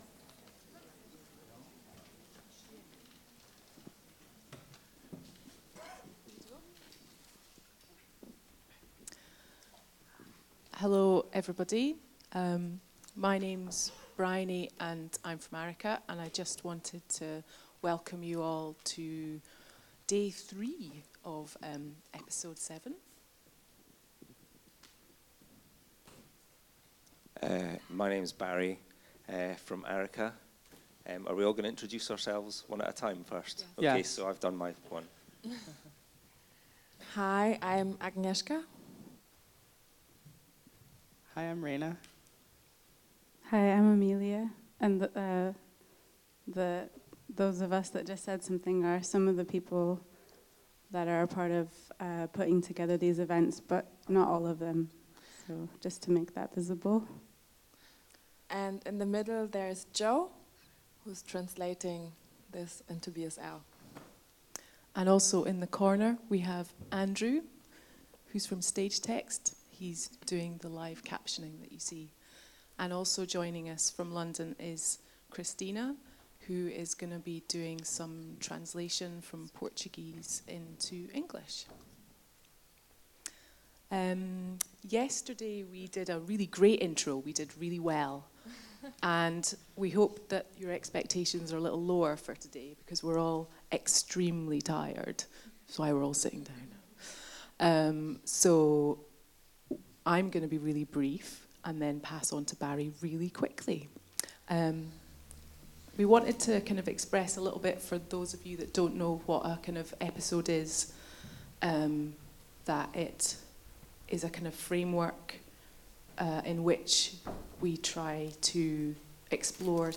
An open conversation around the history and practices of the Ueinzz Theatre Company – a radical Brazilian schizoscenic theatre company of carers, so-called psychotic patients and philosophers.